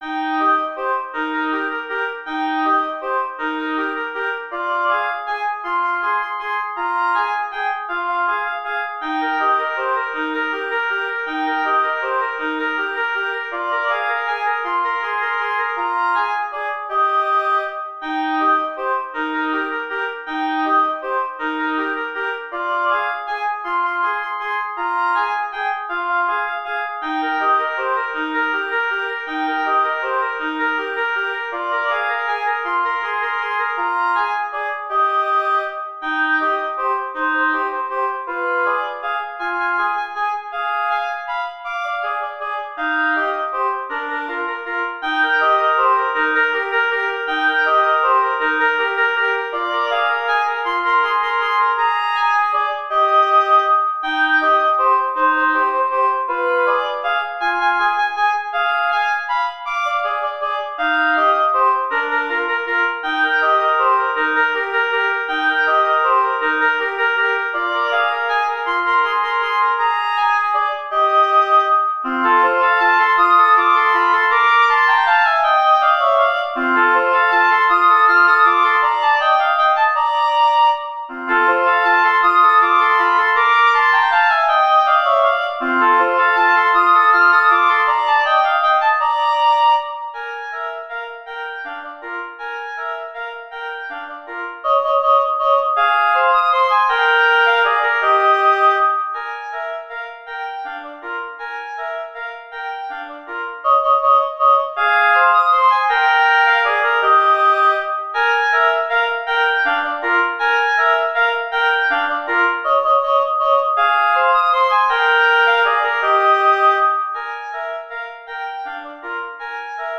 Free Sheet music for Oboe Duet
Eb major (Sounding Pitch) (View more Eb major Music for Oboe Duet )
3/4 (View more 3/4 Music)
Traditional (View more Traditional Oboe Duet Music)